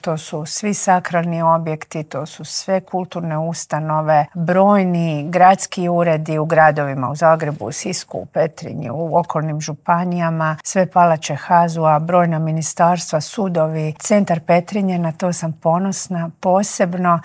Treba 'ohladiti glave' i spustiti tenzije, zaključila je na kraju intervjua ministrica Obuljen Koržinek.